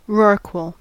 Uttal
Uttal US
IPA : /ˈrɒrkwəl/